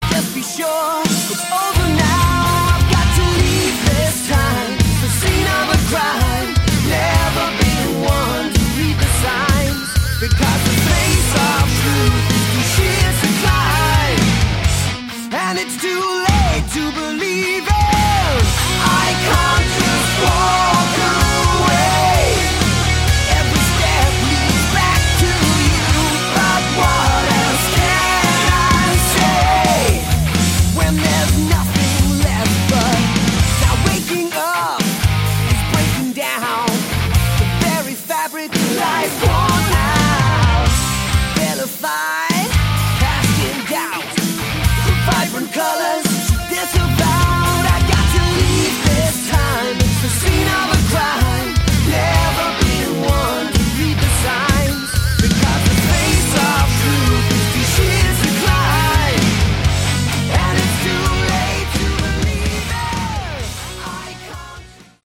Category: Hard Rock
guitar
vocals
bass
drums